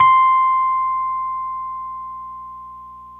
RHODES CL0IR.wav